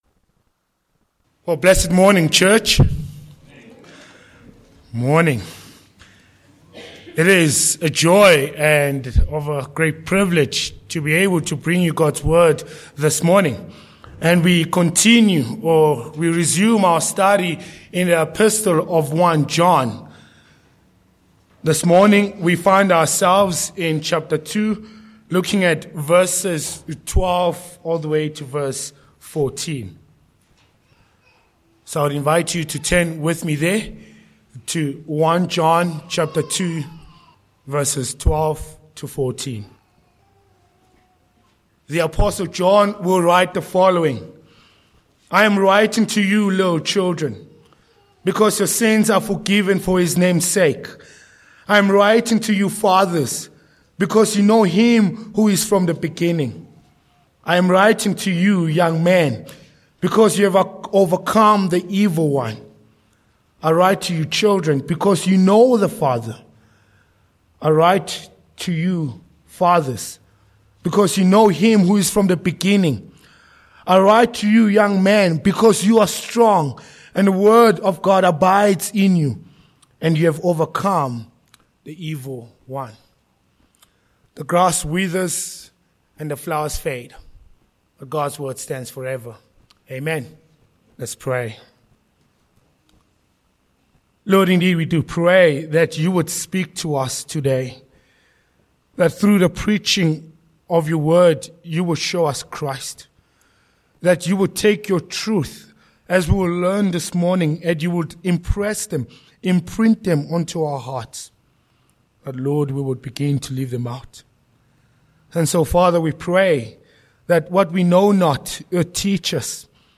Passage: 1 John 2:12-14 Sermon points: 1.
1 John 2:12-14 Service Type: Morning Passage